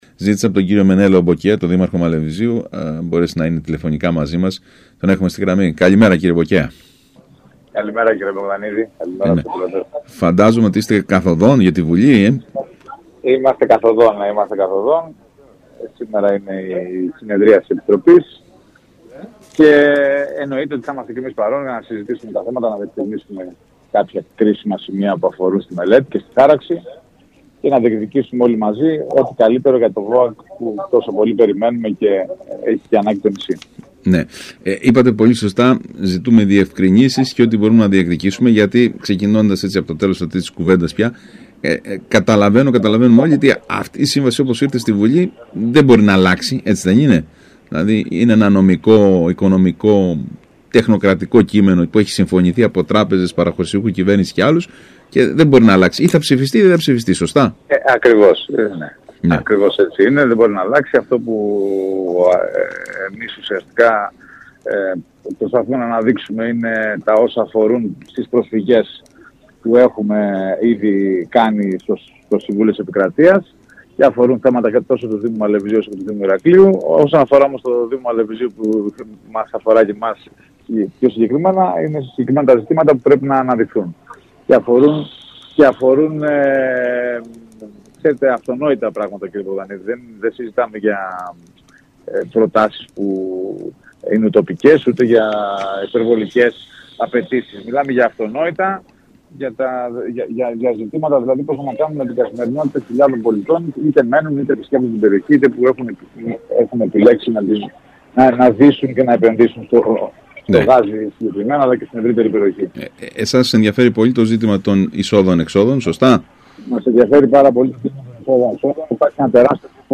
Δεν νομίζω ότι μπορούμε να το διανοηθούμε αυτό», είπε μιλώντας στον ΣΚΑΙ Κρήτης ο δήμαρχος Μαλεβιζίου Μενέλαος Μποκέας, προτάσσοντας τη διάσταση ότι ο δήμος του είναι εκείνος που έχει προσφέρει τα πάντα στην ανάπτυξη της Κρήτης.